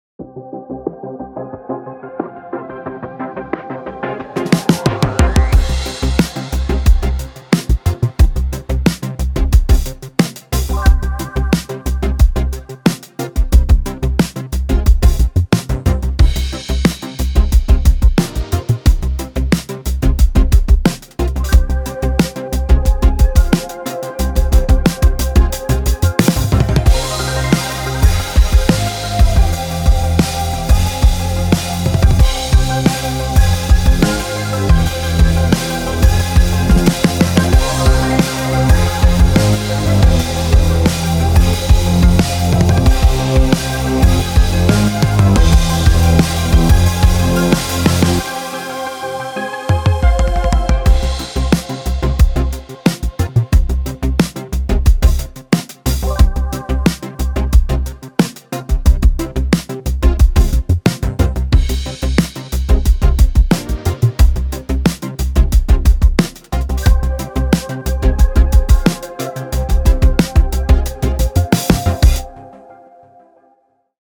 どんなトラックやミックスも、よりハードにヒットするウルトラデッド・ヴィンテージ・ドラムが魂を吹き込む